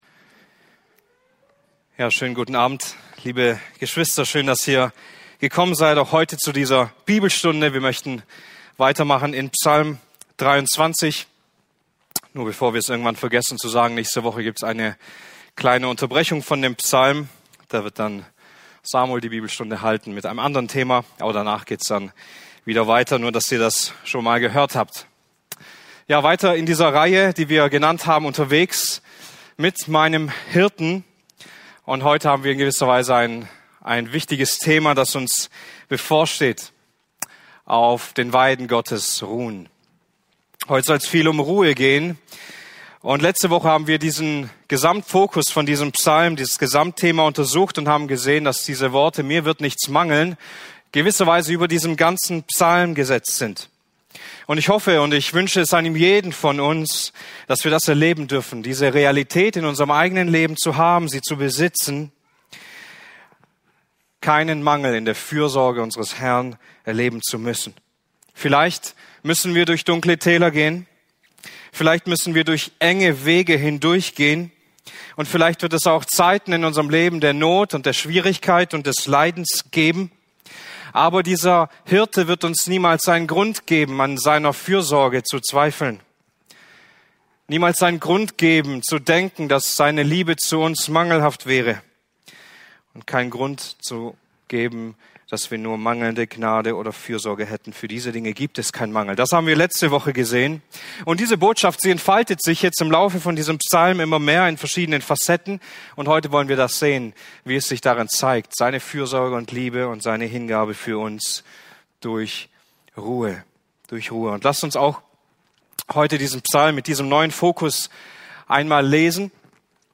Freikirchliche Gemeinde Böbingen | Bibelstunde